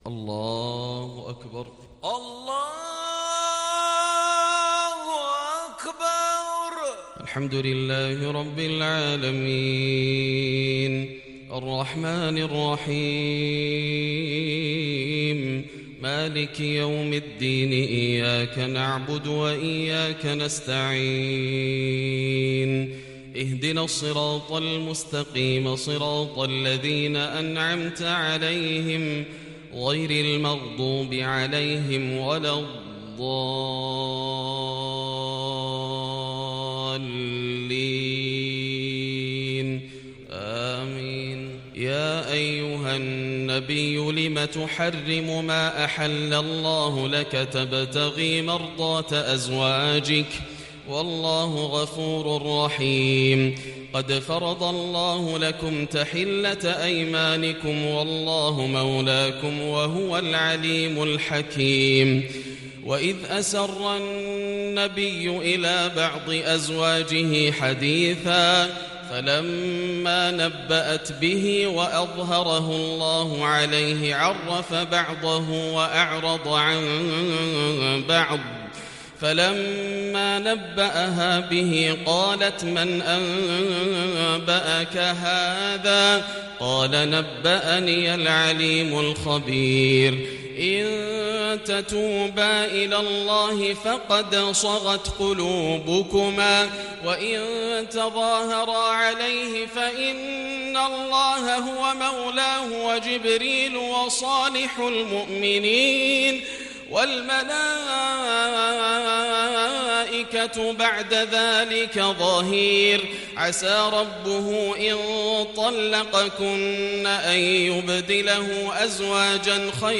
صلاة العشاء للشيخ ياسر الدوسري 28 جمادي الأول 1441 هـ
تِلَاوَات الْحَرَمَيْن .